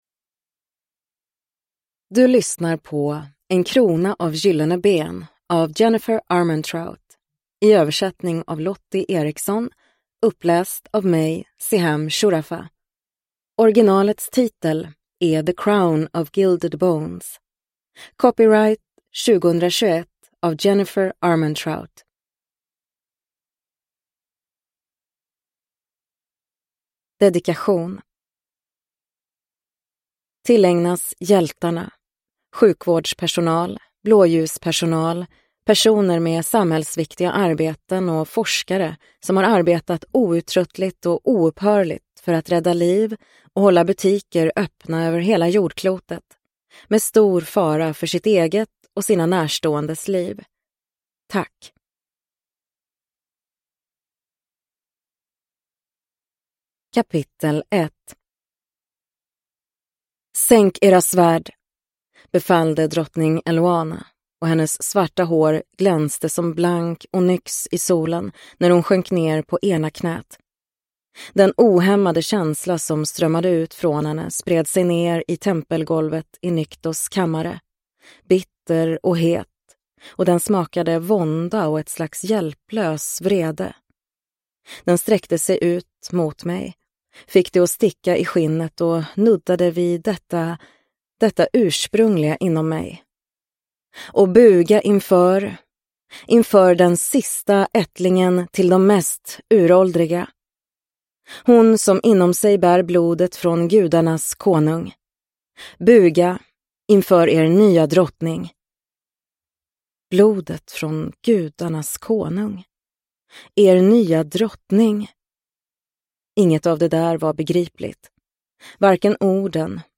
En krona av gyllene ben (ljudbok) av Jennifer L. Armentrout